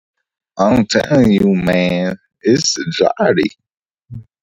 why is the audio so jittery award